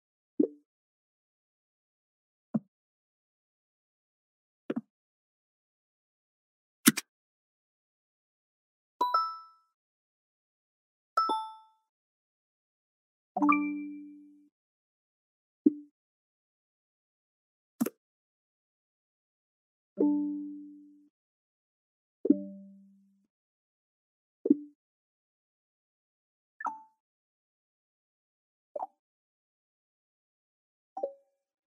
Modern User Interface Sound Effects (Copyright Free)
Copyright free, royalty free modern user interface sound effects.
modern-user-interface-sound-effects.mp3